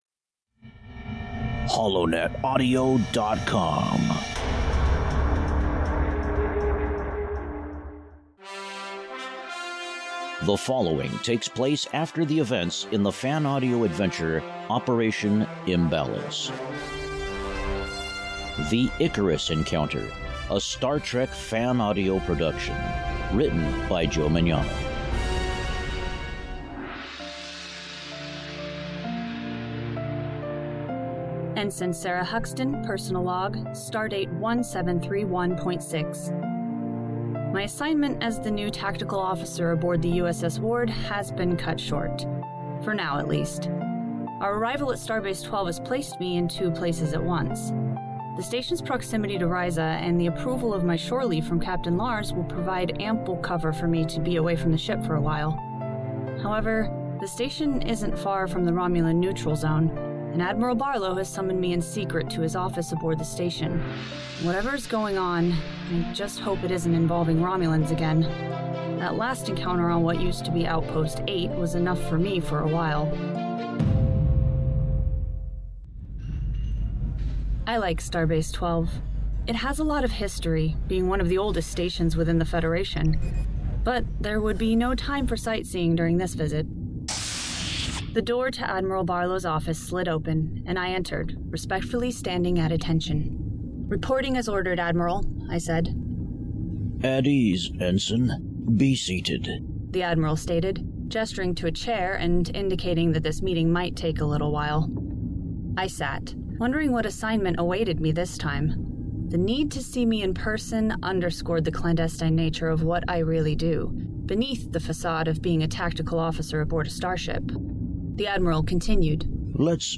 Audio Books/Drama
THE-ICARUS-ENCOUNTER-a-STAR-TREK-fan-audio-production.mp3